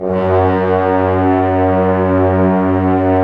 Index of /90_sSampleCDs/Roland - Brass, Strings, Hits and Combos/ORC_Orc.Unison f/ORC_Orc.Unison f